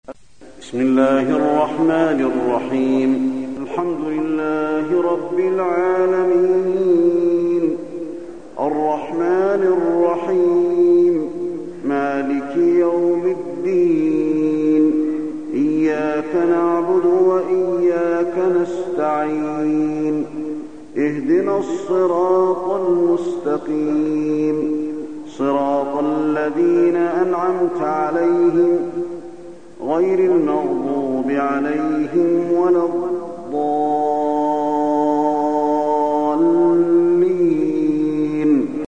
تلاوة سورة الفاتحة بصوت الشيخ علي بن عبد الرحمن الحذيفي
تاريخ النشر ١ رمضان ١٤٢٣ هـ المكان: المسجد النبوي الشيخ: فضيلة الشيخ د. علي بن عبدالرحمن الحذيفي فضيلة الشيخ د. علي بن عبدالرحمن الحذيفي سورة الفاتحة